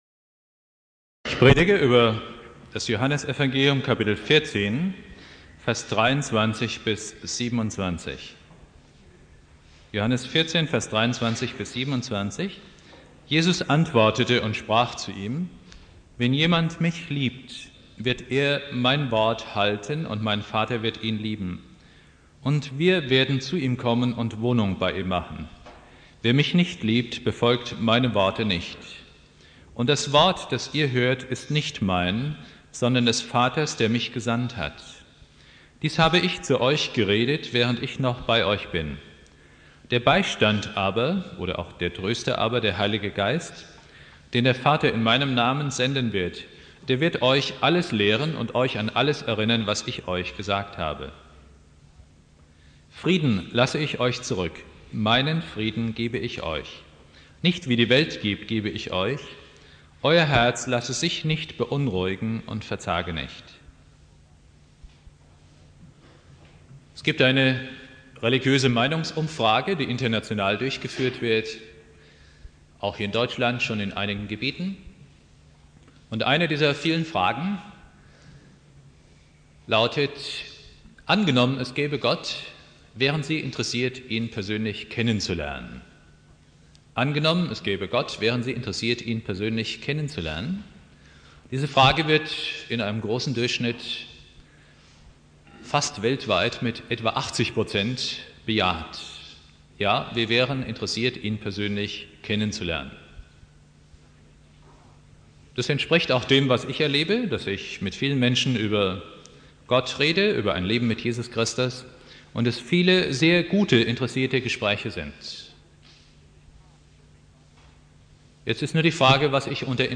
Predigt
Pfingstsonntag